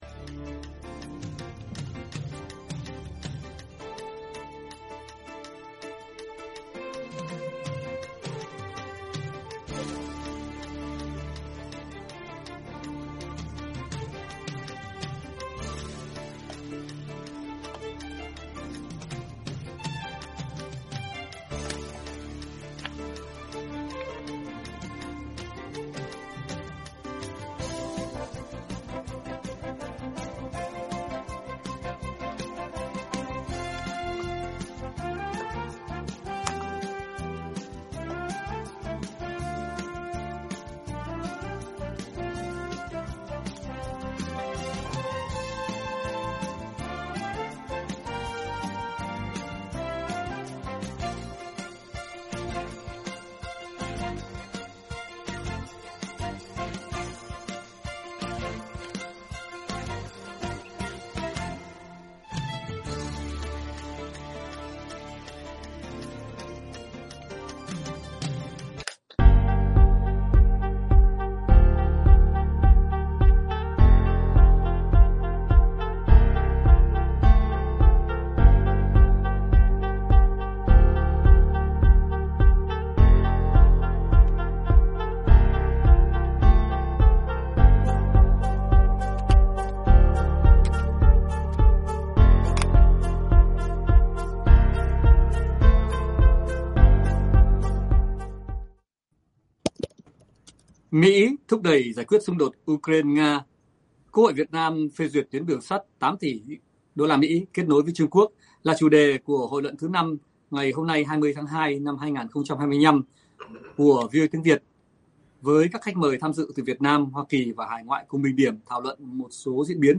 Các khách mời tham dự từ Hoa Kỳ và hải ngoại cùng bình điểm, thảo luận một số diễn biến, sự kiện và vấn đề nổi bật, được quan tâm trong tuần, kính thưa và kính chào quý vị khán, thính giả.